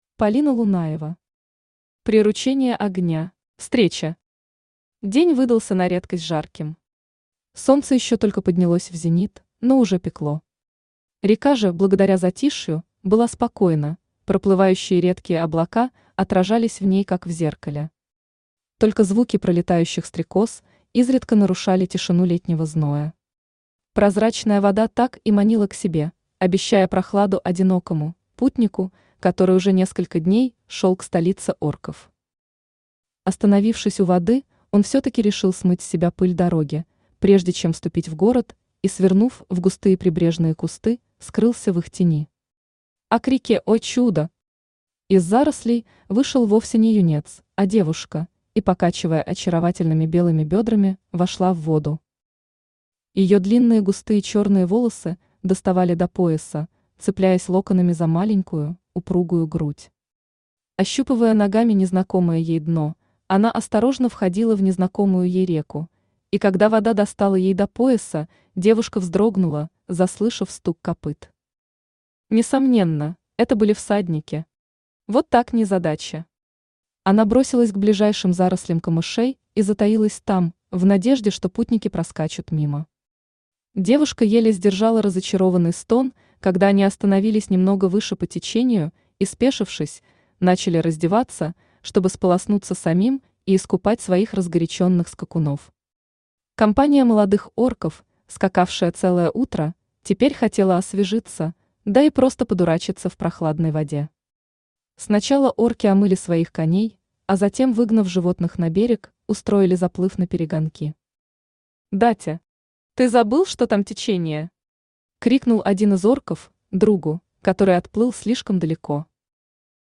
Аудиокнига Приручение огня | Библиотека аудиокниг
Aудиокнига Приручение огня Автор Полина Лунаева Читает аудиокнигу Авточтец ЛитРес.